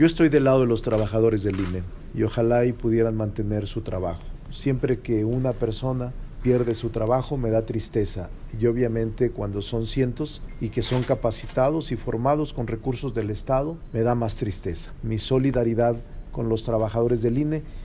En entrevista, el presidente de la Junta de Coordinación Política, aseveró que algunos consejeros del Instituto Nacional Electoral (INE) tienen un protagonismo exacerbado.